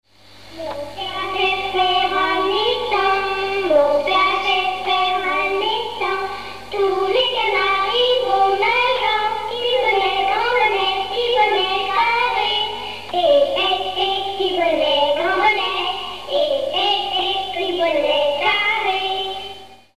Seytroux ( Plus d'informations sur Wikipedia ) Haute-Savoie
Pièce musicale inédite